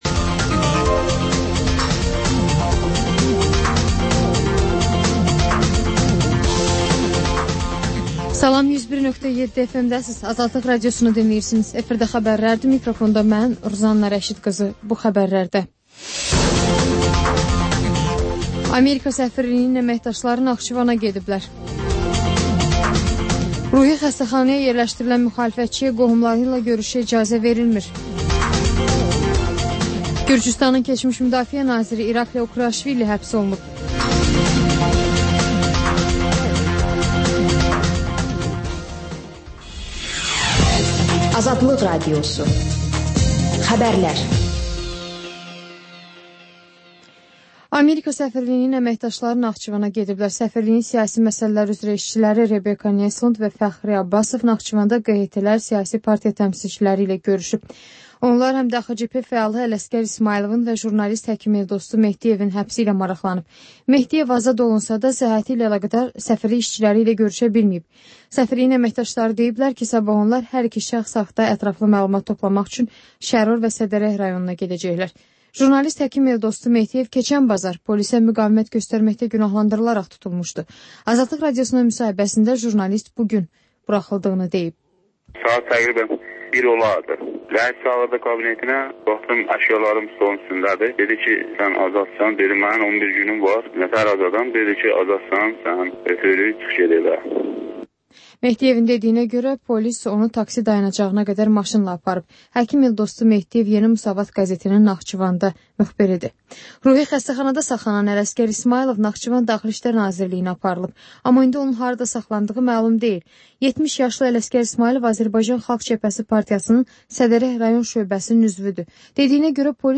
Xəbərlər, müsahibələr, hadisələrin müzakirəsi, təhlillər, sonda QAFQAZ QOVŞAĞI rubrikası: «Azadlıq» Radiosunun Azərbaycan, Ermənistan və Gürcüstan redaksiyalarının müştərək layihəsi